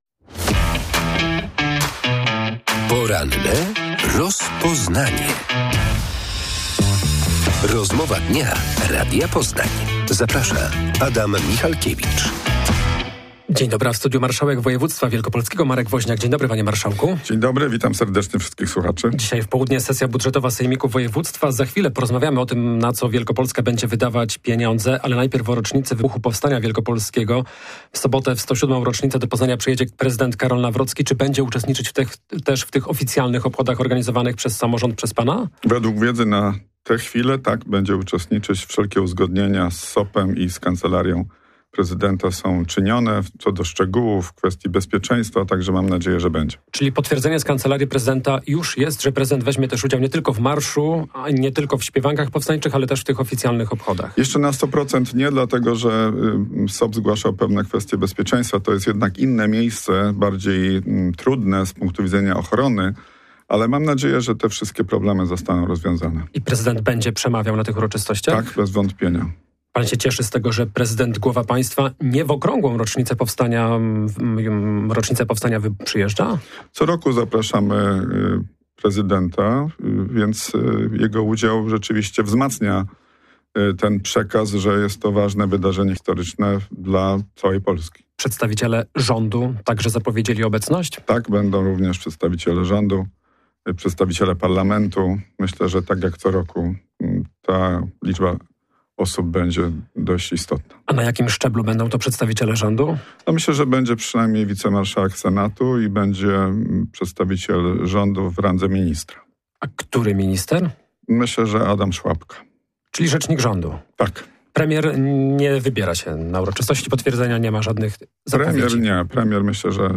Prezydent Karol Nawrocki ma w sobotę w Poznaniu uczestniczyć także w oficjalnych obchodach 107. rocznicy wybuchu Powstania Wielkopolskiego. Informacje potwierdził w porannej rozmowie Radia Poznań marszałek województwa Marek Woźniak.